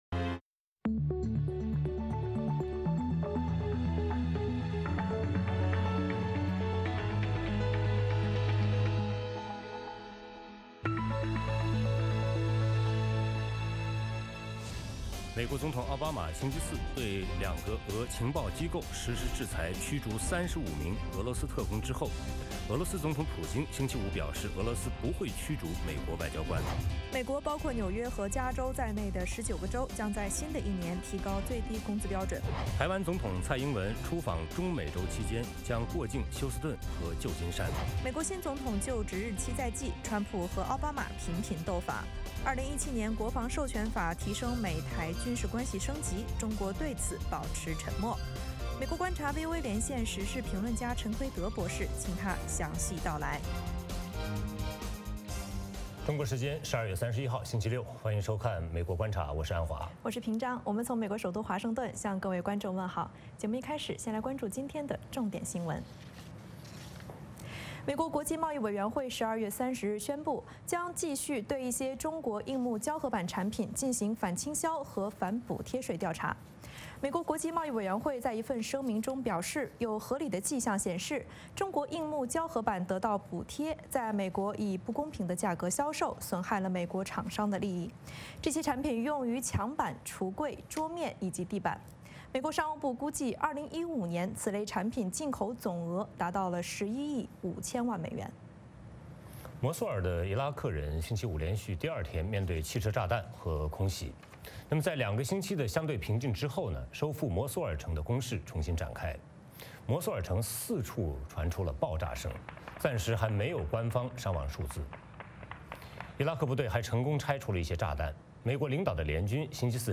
美国之音中文广播于北京时间早上8－9点重播《VOA卫视》节目(电视、广播同步播出)。
“VOA卫视 美国观察”掌握美国最重要的消息，深入解读美国选举，政治，经济，外交，人文，美中关系等全方位话题。节目邀请重量级嘉宾参与讨论。